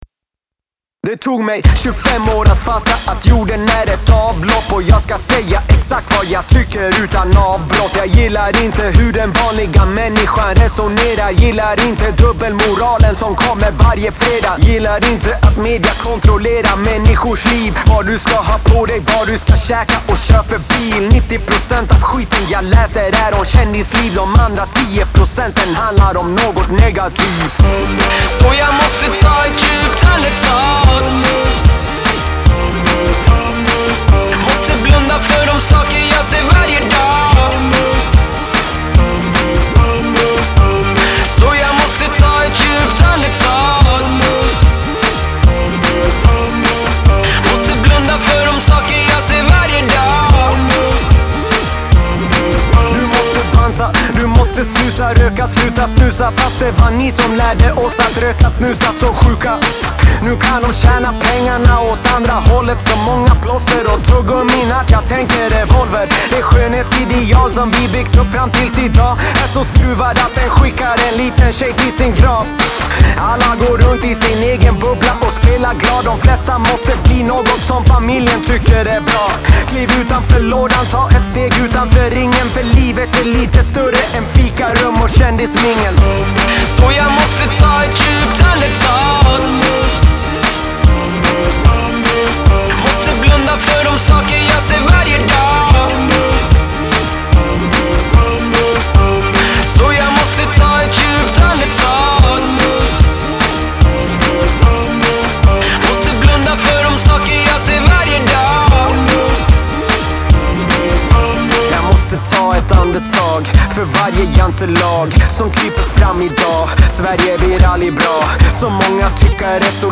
Swedish hip-hop